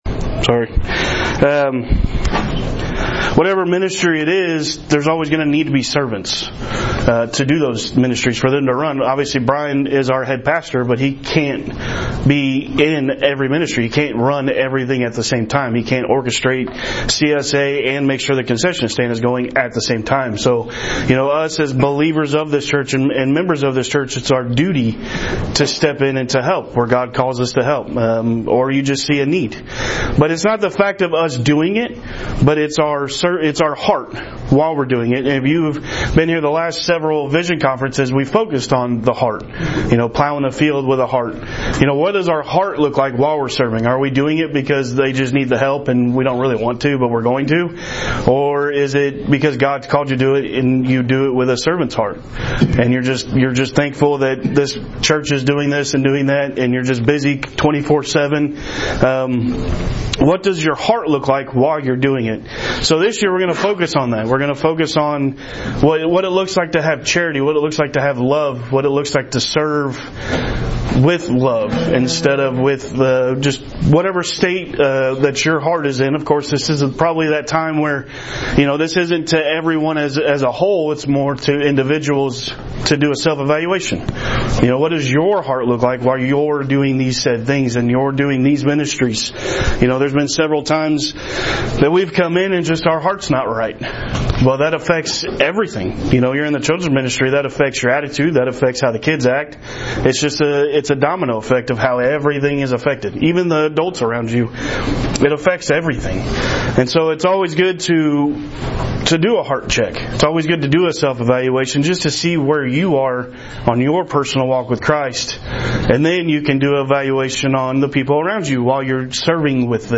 Men's Breakfast | Heartland Baptist Fellowship
Guest Speaker